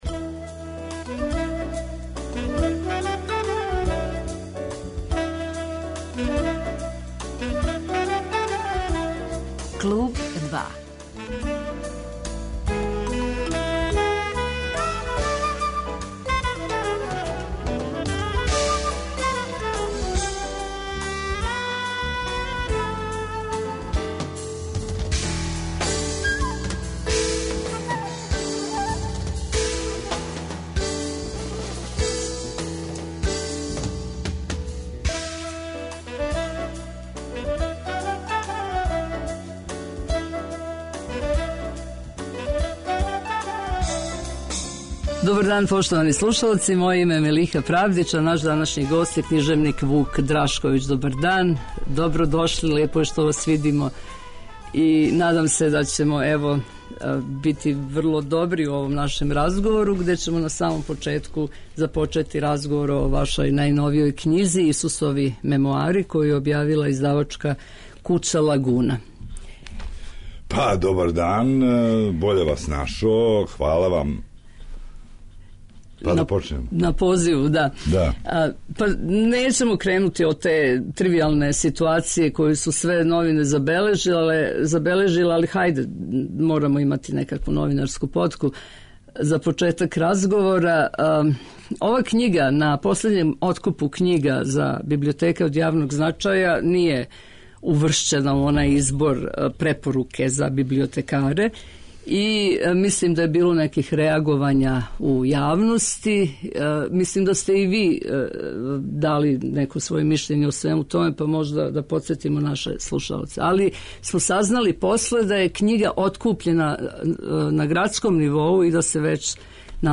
Гост емисије 'Клуб 2' је књижевник Вук Драшковић